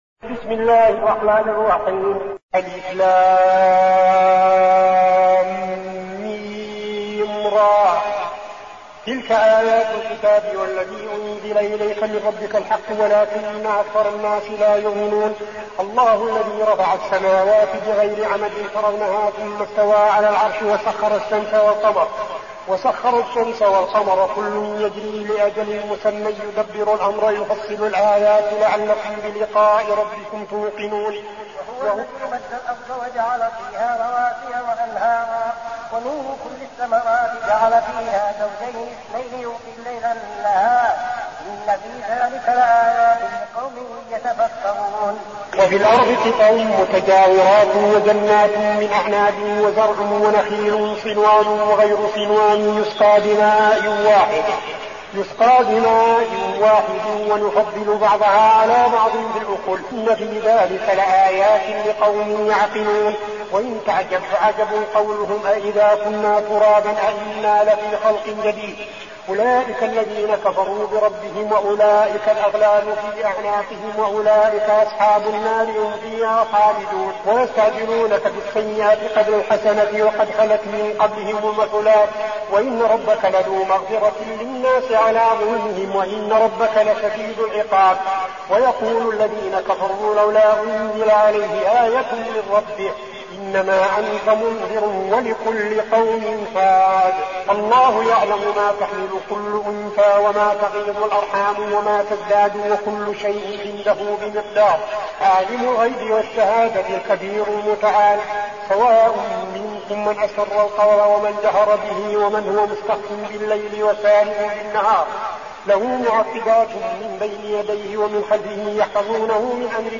المكان: المسجد النبوي الشيخ: فضيلة الشيخ عبدالعزيز بن صالح فضيلة الشيخ عبدالعزيز بن صالح الرعد